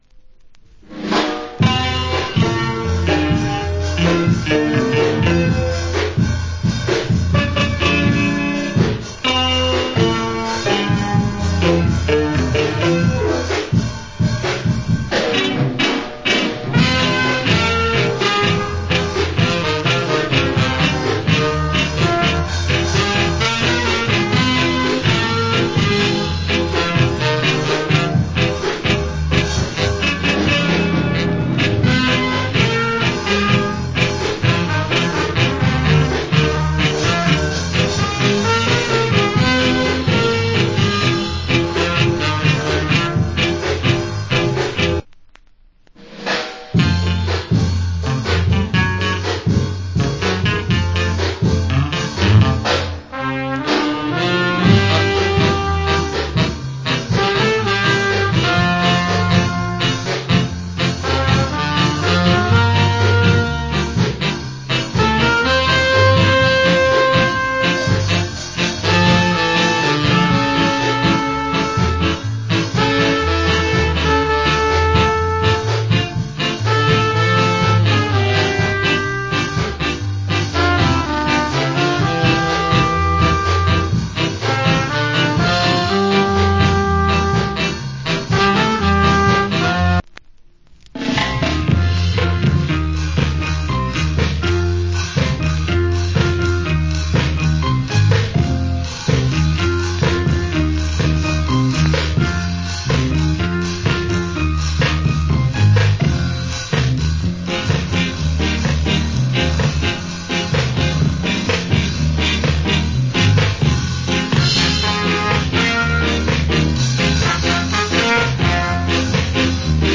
Great Ska Inst. 1984.